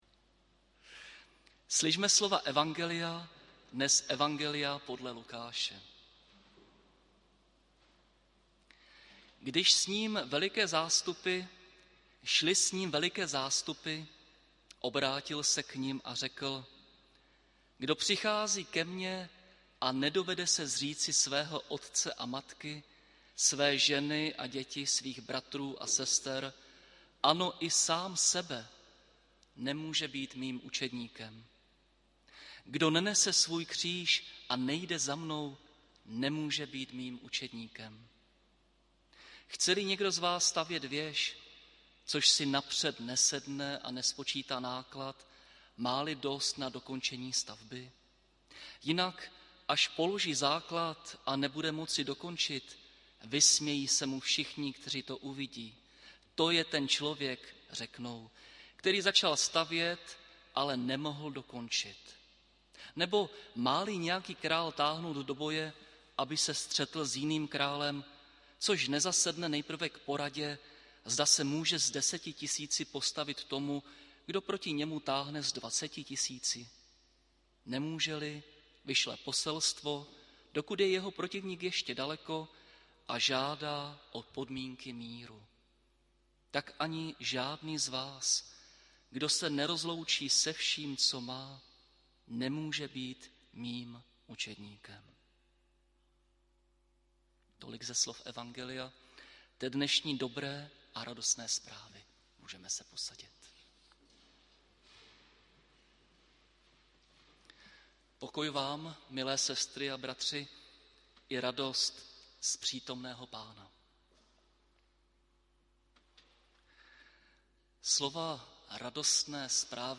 Bohoslužby s VP 4. 9. 2022 • Farní sbor ČCE Plzeň - západní sbor
audio kázání